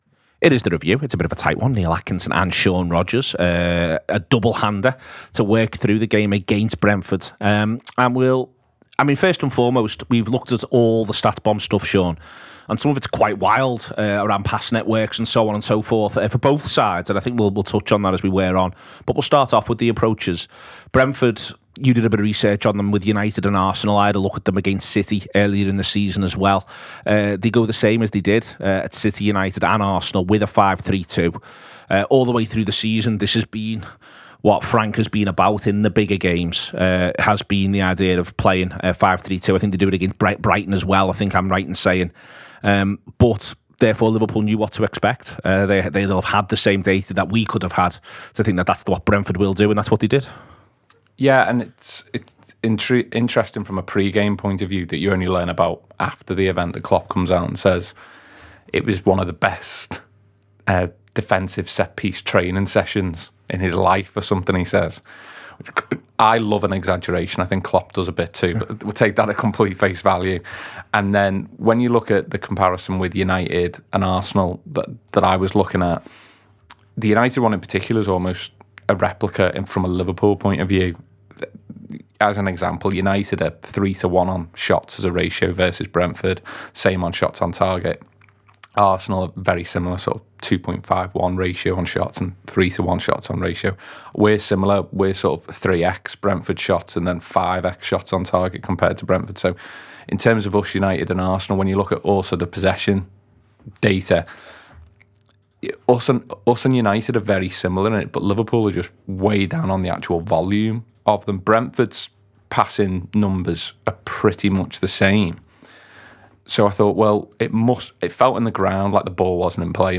Below is a clip from the show – subscribe for more review chat around Liverpool 1 Brentford 0…